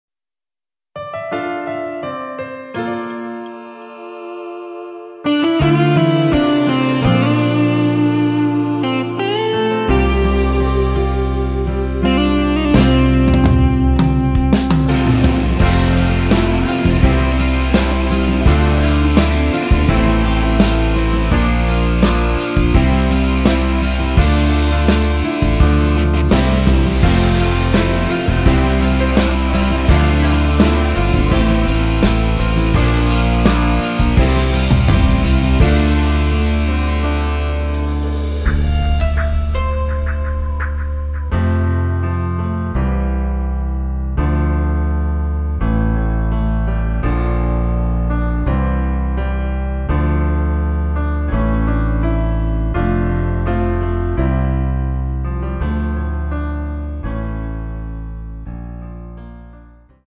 코러스 포함된 MR 입니다.(미리듣기 참조)
앞부분30초, 뒷부분30초씩 편집해서 올려 드리고 있습니다.
중간에 음이 끈어지고 다시 나오는 이유는